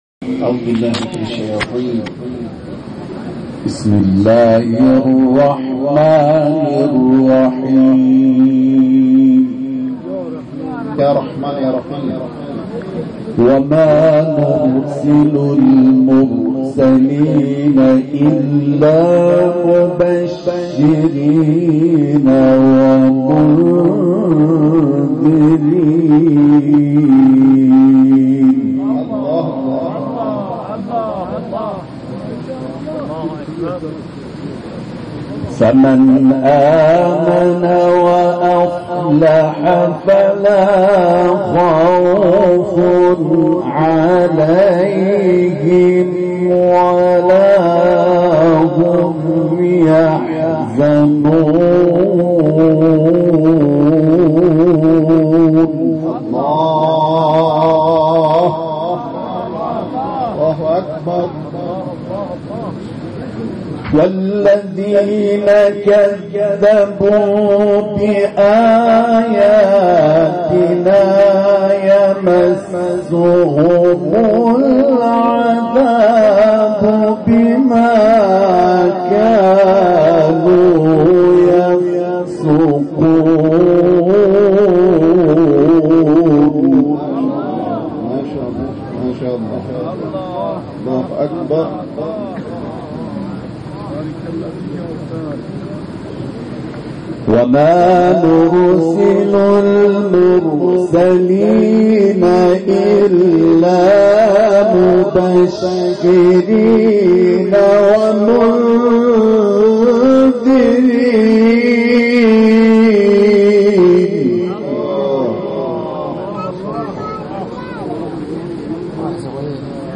تلاوت سوره «انعام»
قاری بین‌المللی قرآن کریم آیات 48 تا 55 سوره «انعام» را تلاوت کرده است.